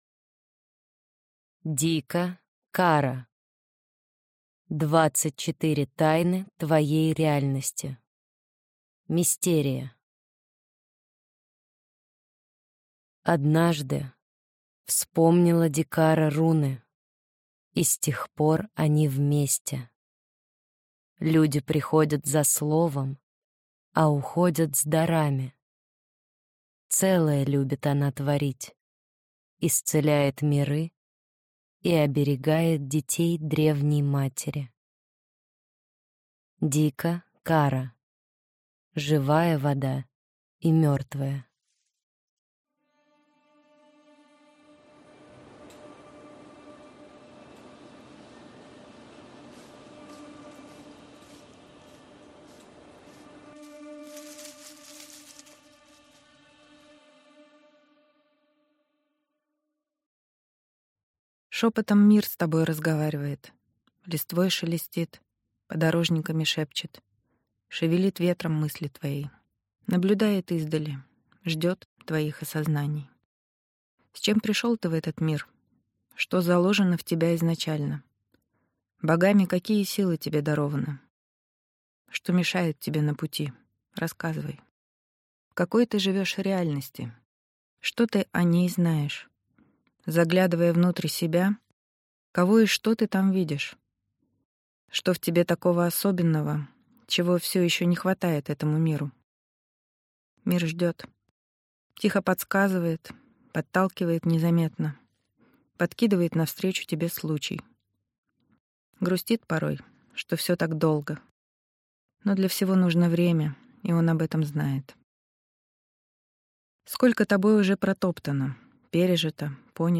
Аудиокнига 24 тайны твоей реальности | Библиотека аудиокниг